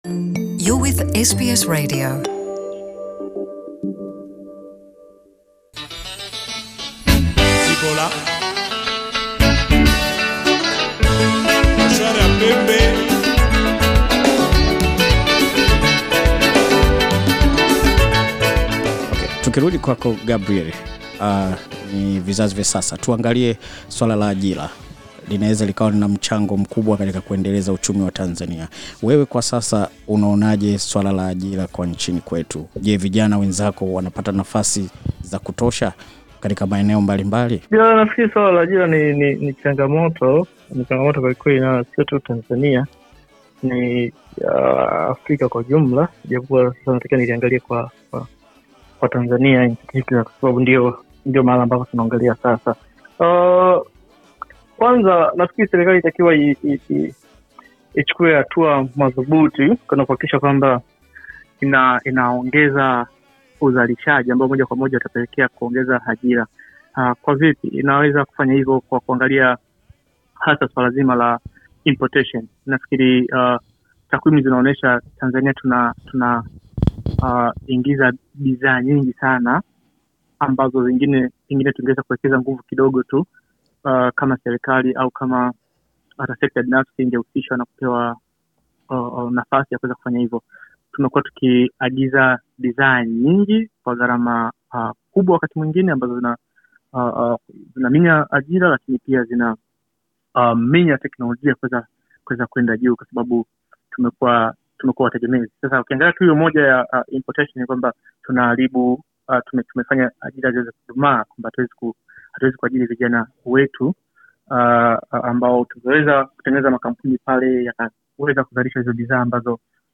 Kumekuwa na maswali mengi juu ya ukuaji wa uchumi wa Tanzania. SBS Swahili ilipata wasaa wa kuzungumza na baadhi ya wafanyabiashara wa kimataifa kuhusiana na mwenendo huo mzima wa uchumi.